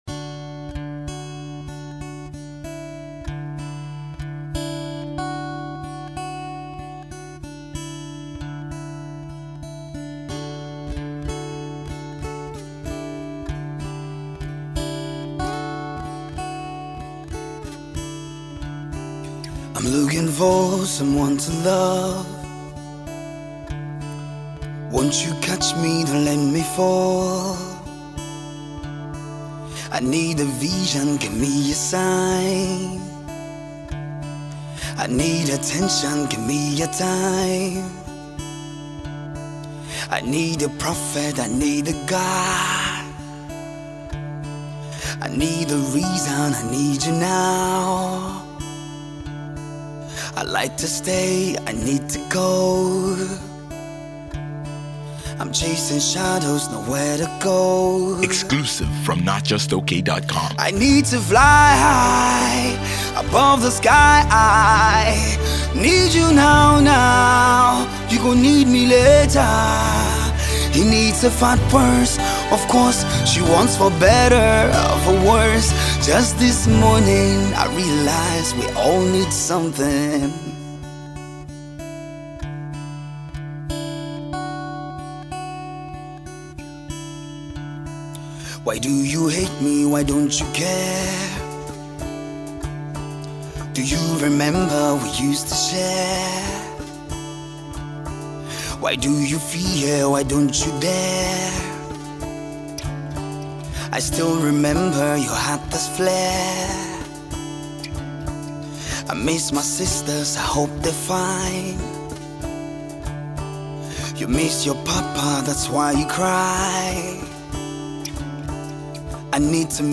lovely acoustic tune
acoustic song
guitar